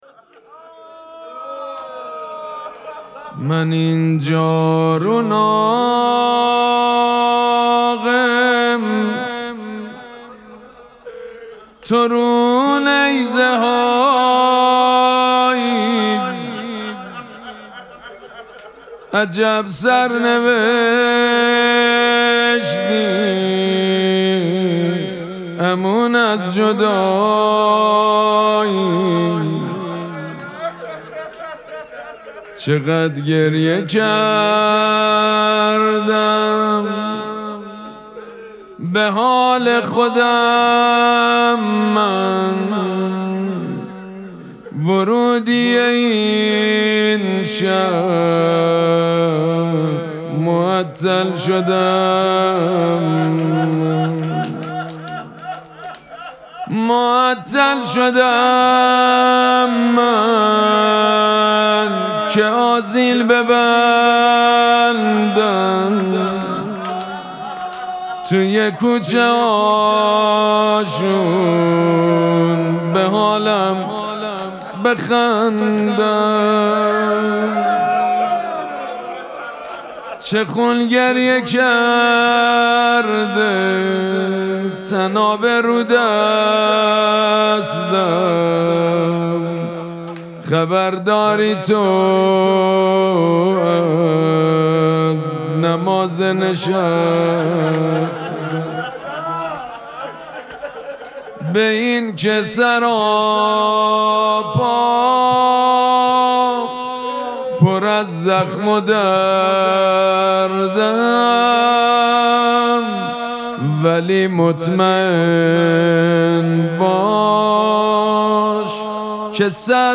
مراسم مناجات شب هجدهم ماه مبارک رمضان
حسینیه ریحانه الحسین سلام الله علیها
روضه
حاج سید مجید بنی فاطمه